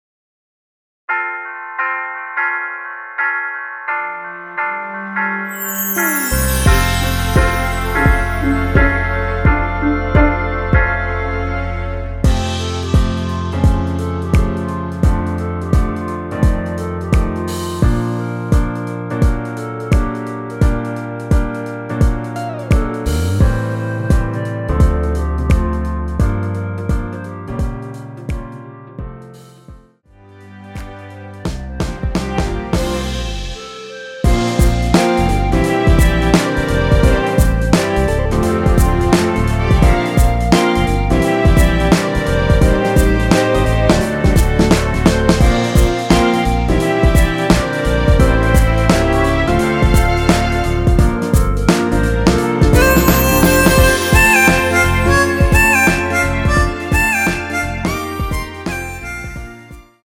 엔딩이 페이드 아웃이라서 노래하기 편하게 엔딩을 만들어 놓았으니 코러스 MR 미리듣기 확인하여주세요!
원키에서(-3)내린 멜로디 포함된 MR입니다.
앞부분30초, 뒷부분30초씩 편집해서 올려 드리고 있습니다.